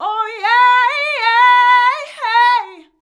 OHYEAHIYEA.wav